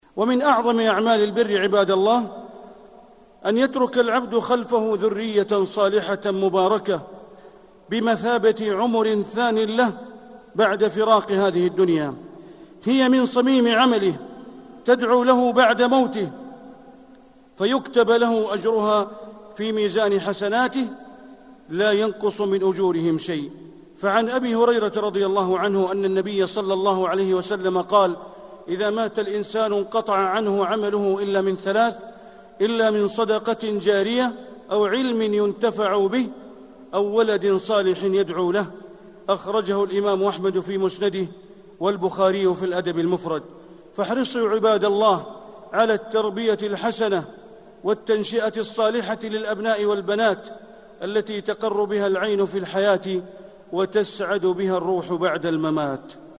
قطعة من خطبة الجمعة في الحرم المكي : الانتفاع بالعمر للآخرة.